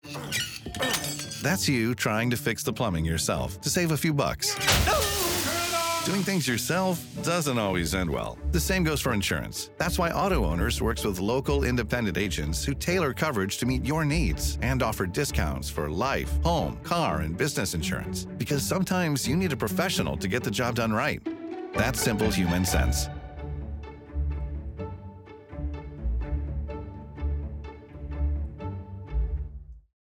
Agent Insurance Radio Ads